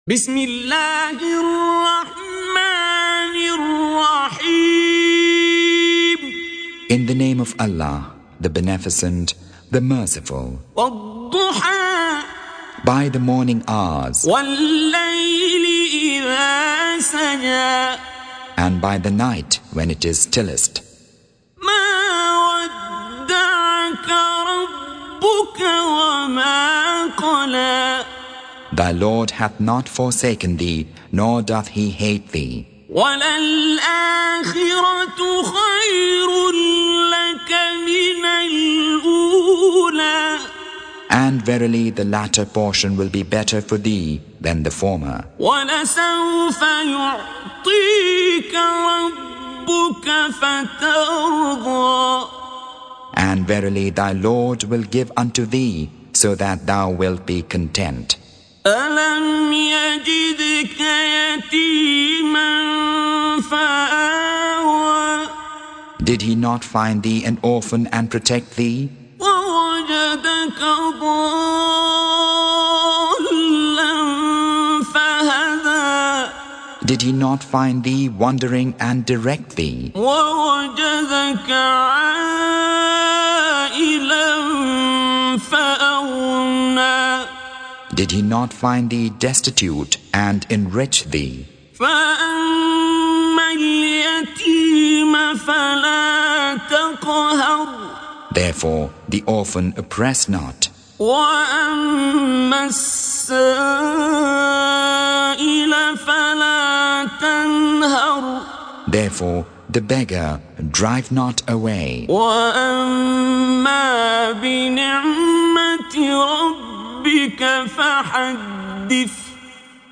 Surah Sequence تتابع السورة Download Surah حمّل السورة Reciting Mutarjamah Translation Audio for 93. Surah Ad-Duha سورة الضحى N.B *Surah Includes Al-Basmalah Reciters Sequents تتابع التلاوات Reciters Repeats تكرار التلاوات